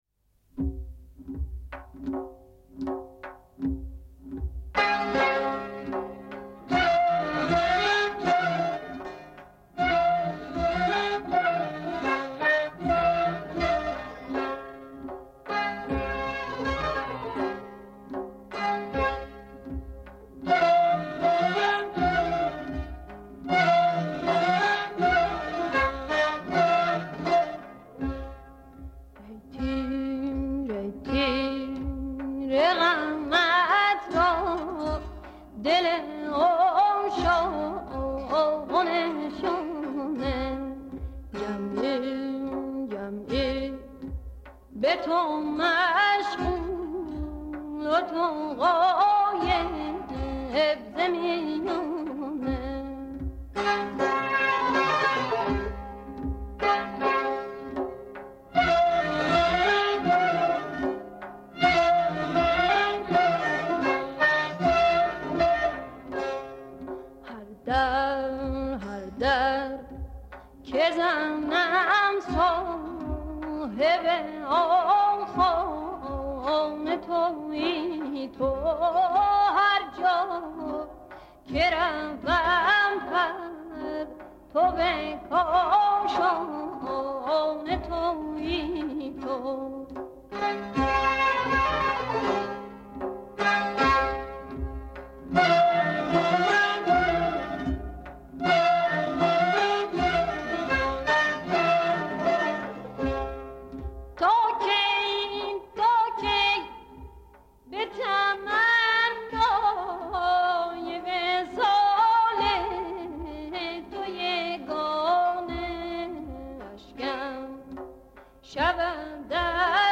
چهارمضراب سنتور، آواز با کمانچه، آواز با سه تار
چهارمضراب تار و نی